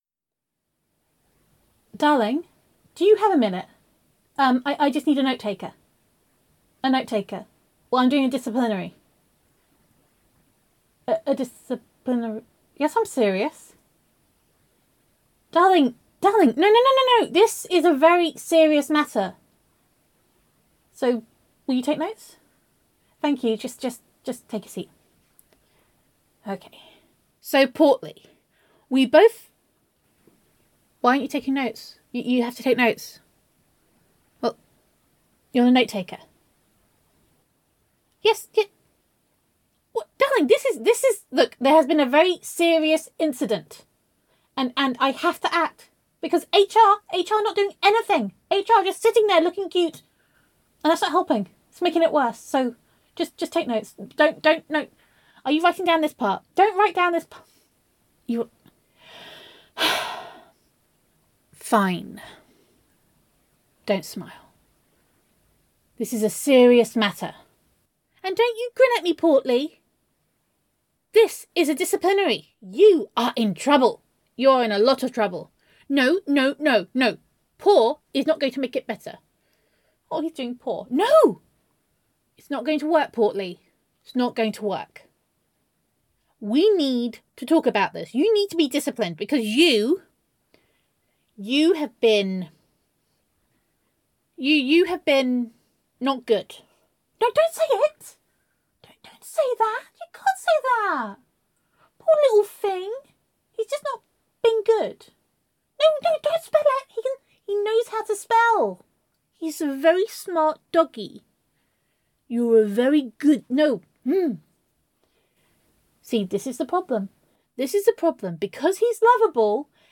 [F4A] Day Two - I Need a Notetaker [Girlfriend Roleplay][Self Quarantine][Domestic Bliss][Gender Neutral][Self-Quarantine With Honey]